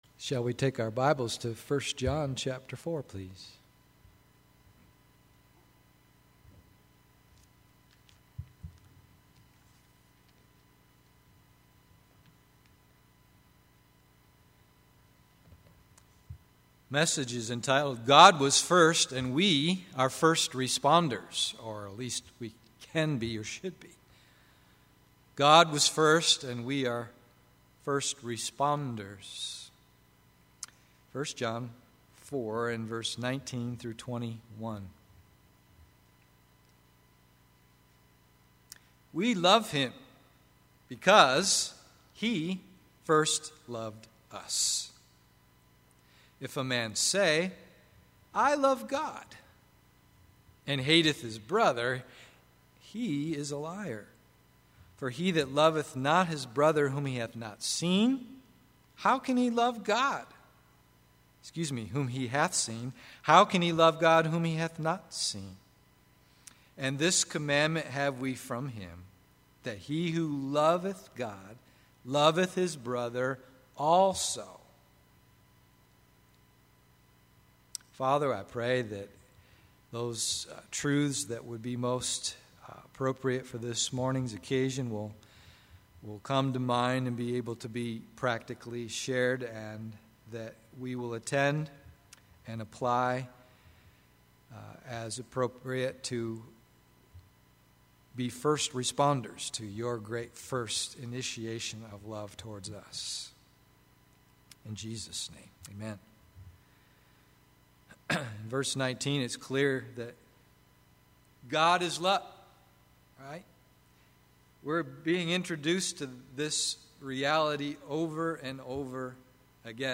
God was First and We are First Responders AM Service